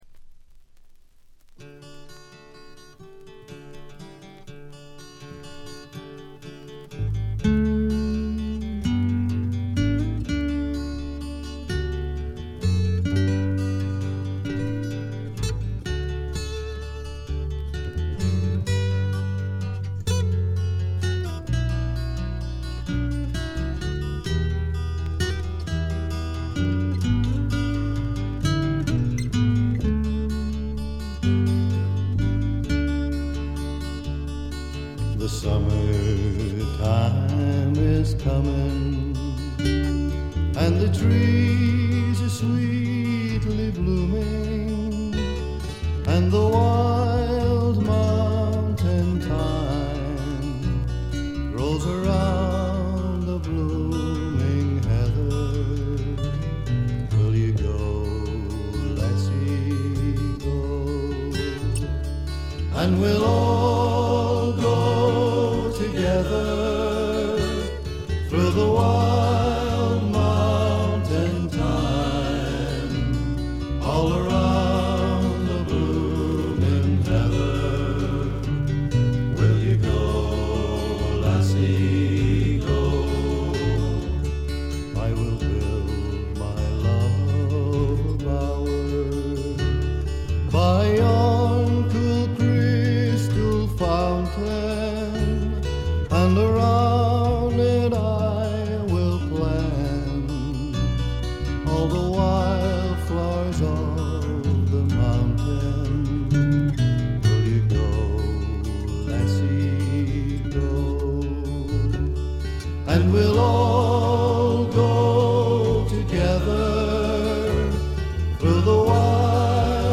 ほとんどノイズ感無し。
試聴曲は現品からの取り込み音源です。
Synthesizer